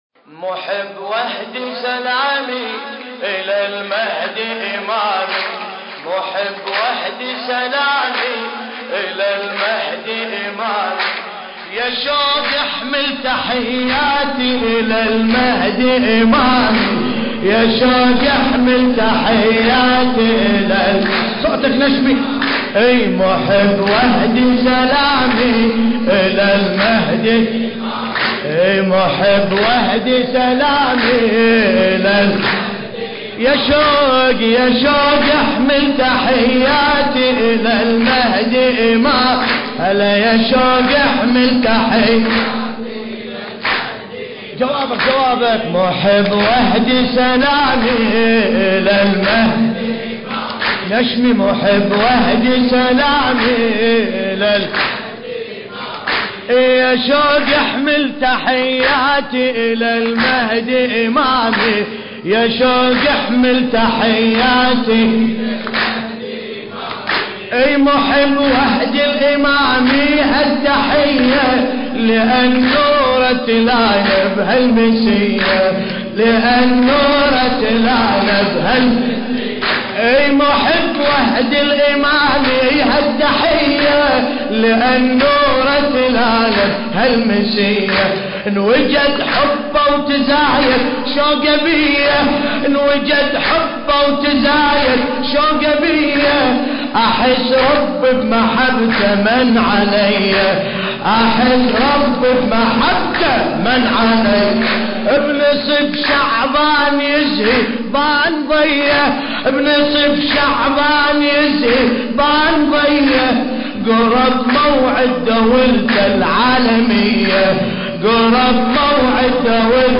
المكان: مأتم ابا الفضل العباس عليه السلام/ البحرين